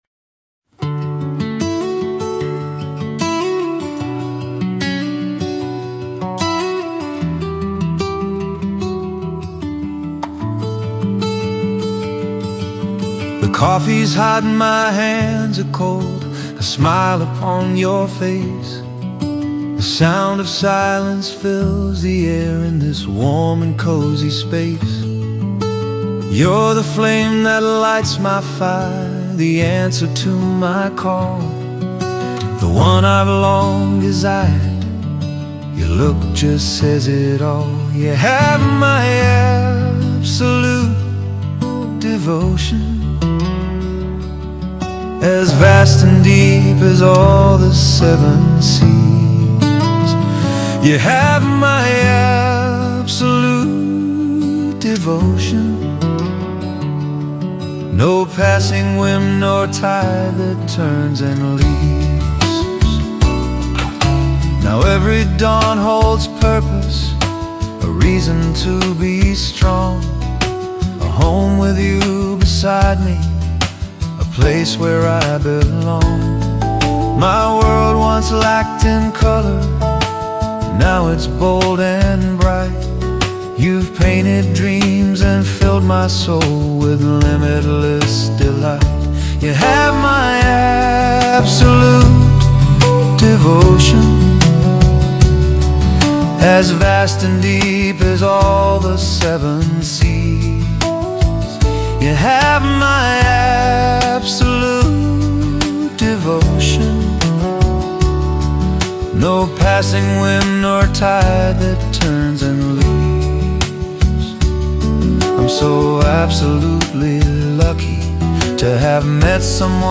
It’s a country song which is unusual for me.